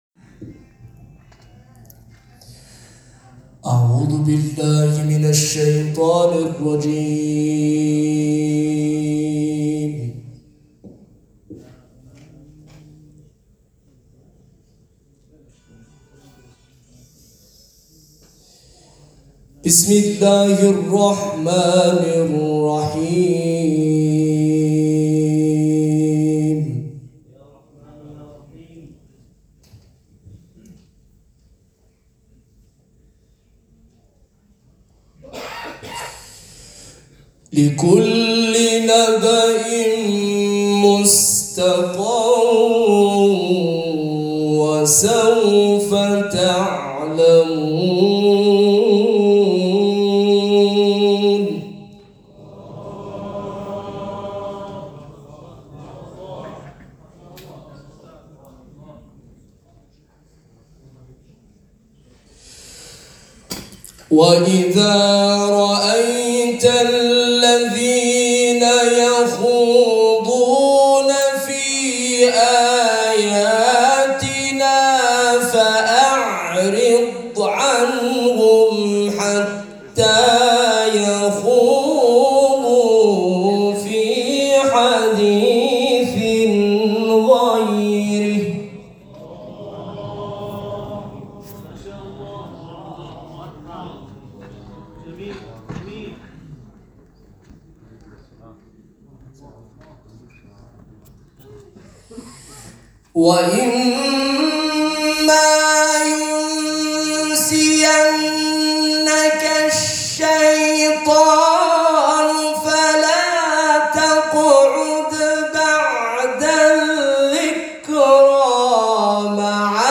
تلاوت
در چهل و پنجمین دوره مسابقات سراسری قرآن